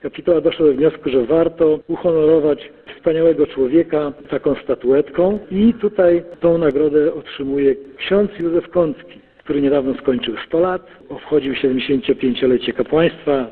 O zwycięzcach mówi Marek Chojnowski, starosta powiatu ełckiego.